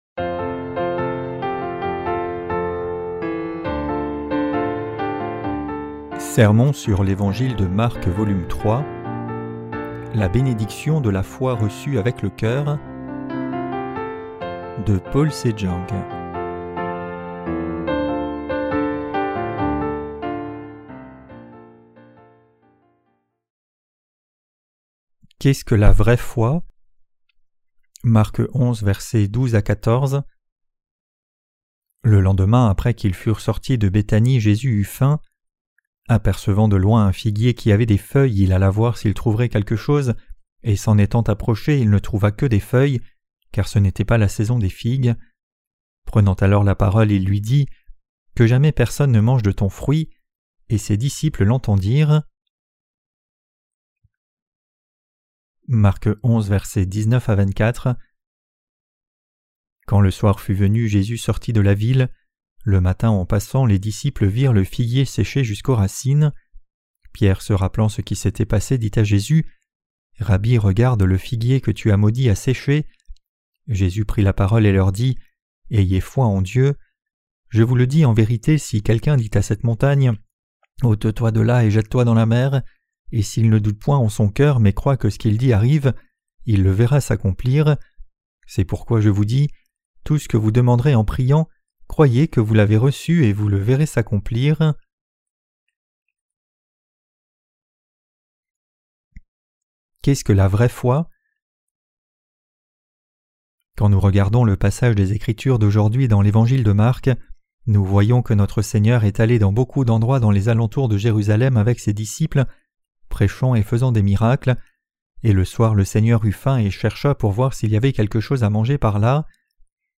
Sermons sur l’Evangile de Marc (Ⅲ) - LA BÉNÉDICTION DE LA FOI REÇUE AVEC LE CŒUR 1.